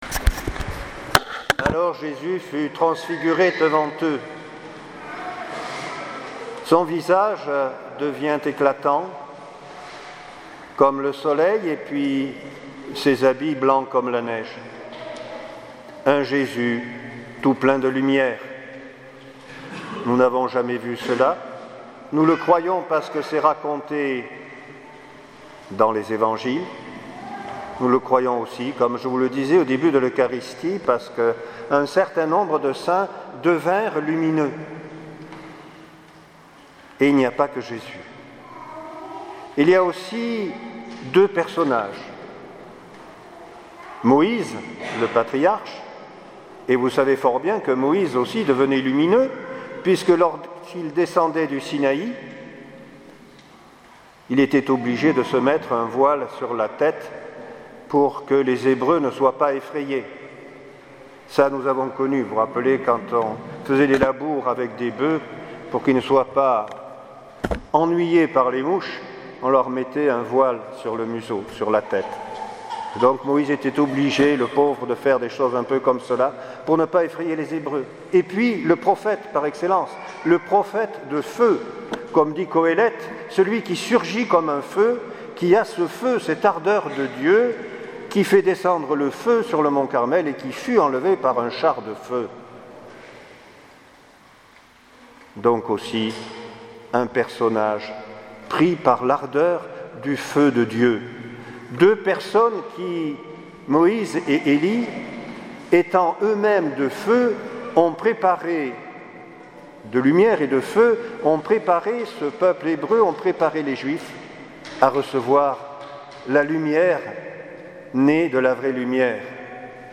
Homélie dimanche 6 août 2017